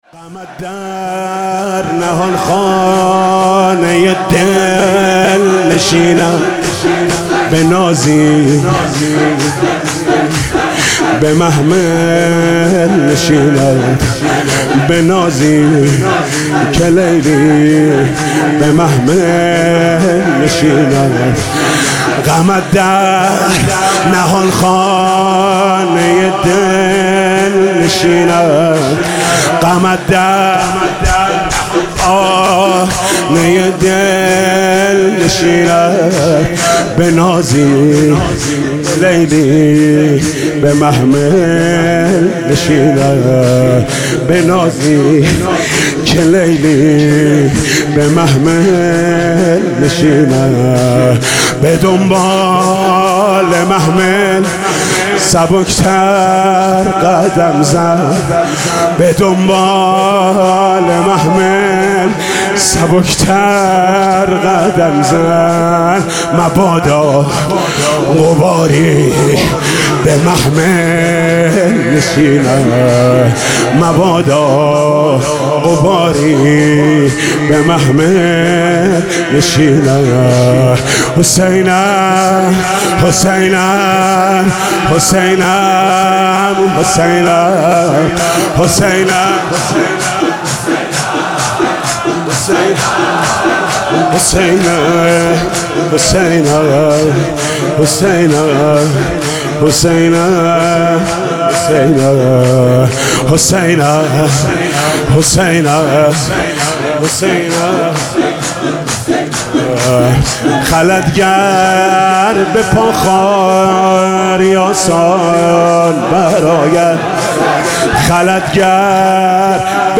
شور: غمت در نهانخانه دل نشیند